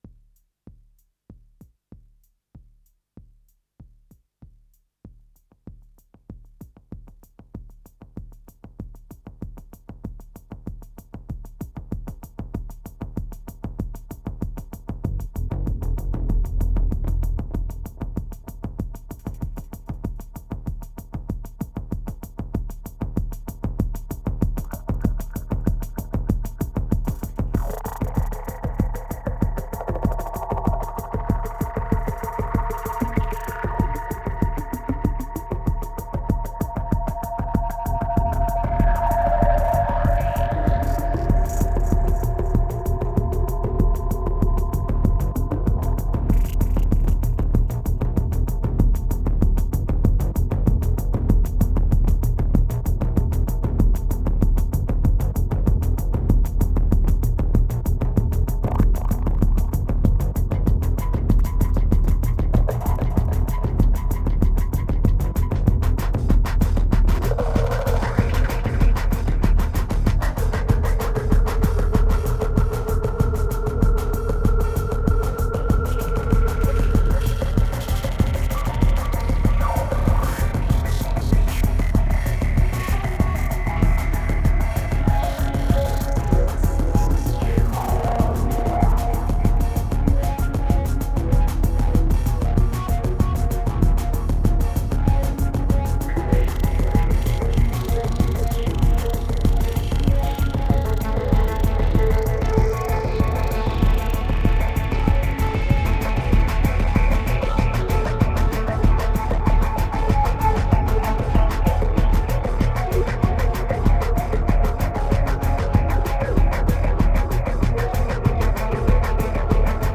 2633📈 - 71%🤔 - 96BPM🔊 - 2010-12-17📅 - 363🌟